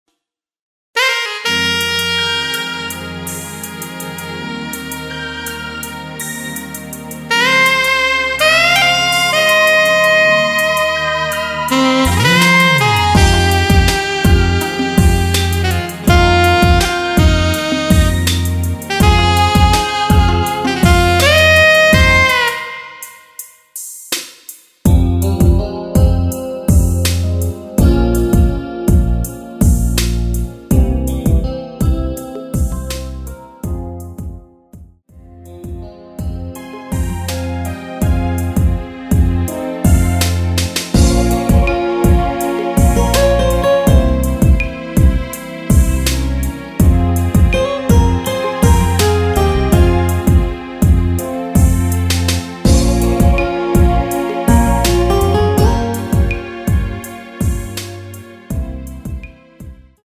원키에서(+1)올린 MR입니다.
Fm
앞부분30초, 뒷부분30초씩 편집해서 올려 드리고 있습니다.